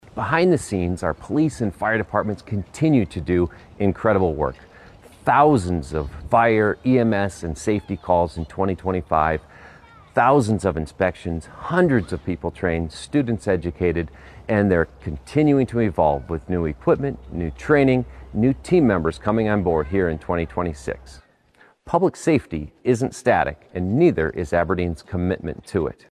The address was delivered at Storybook Land.